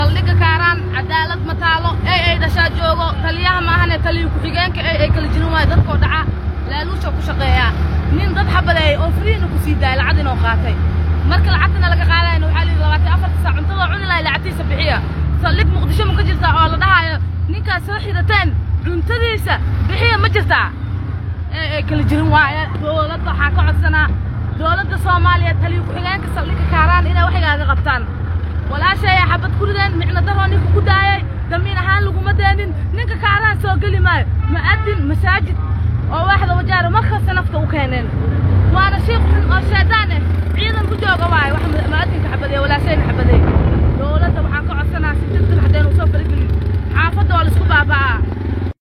Mid ka mid ah haweenka ku nool magaalada muqdisho gaaraan degmada kaaraan ayaa sheegtay in saldhiga degmada kaaran ay ka jirto cadaalad daro.